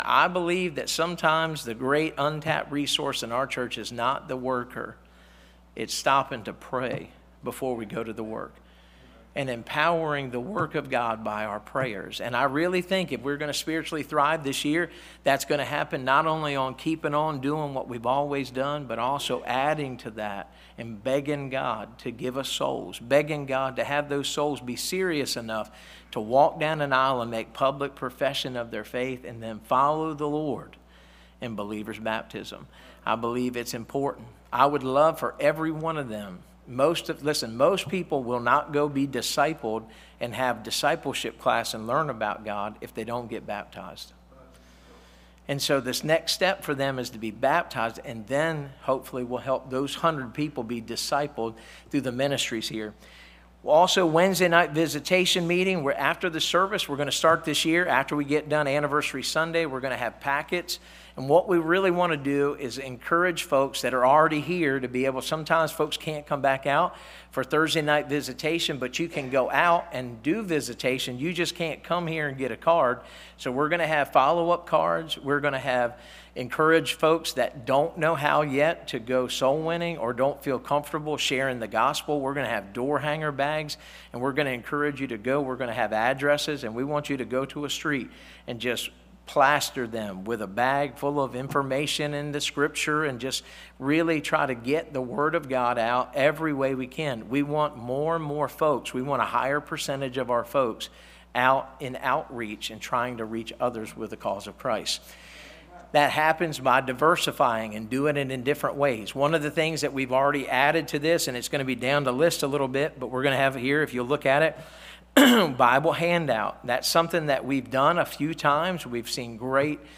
Service Type: Wednesday